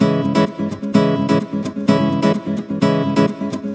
VEH2 Nylon Guitar Kit 128BPM
VEH2 Nylon Guitar Kit - 10 C# min.wav